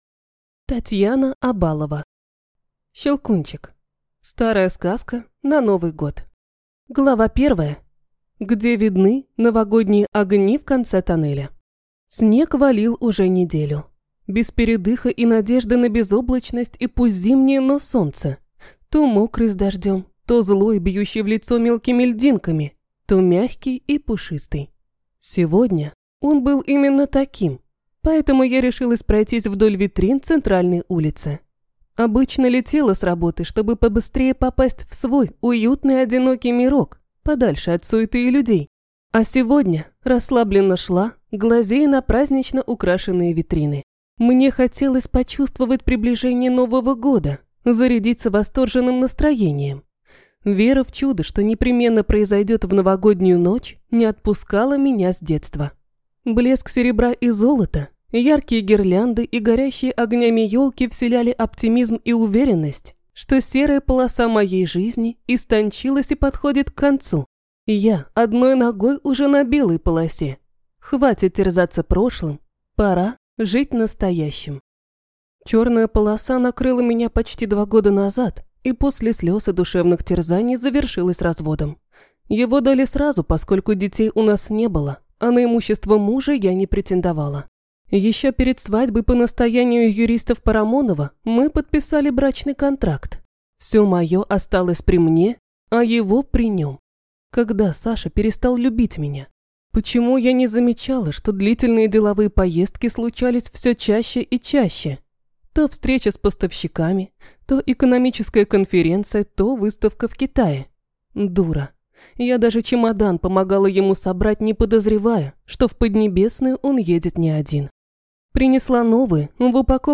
Приемный ребенок (слушать аудиокнигу бесплатно) - автор Дженни Блэкхерст